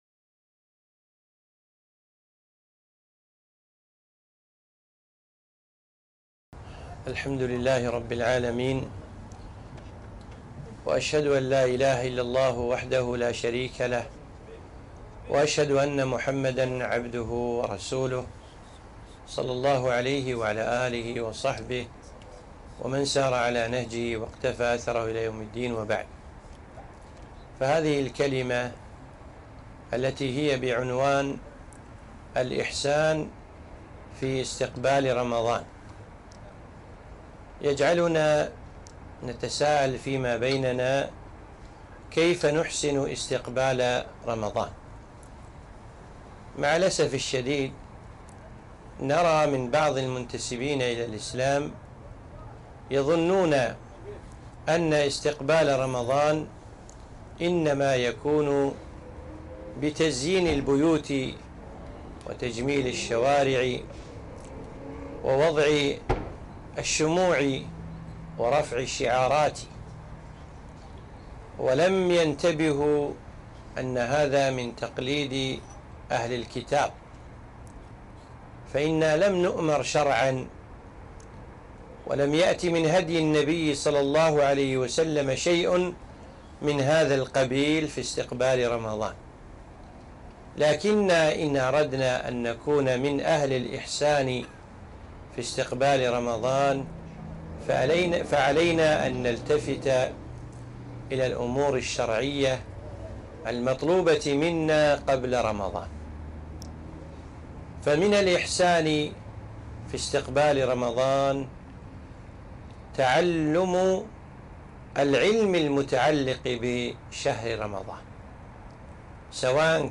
محاضرة - الإحسان في التهيئة لرمضان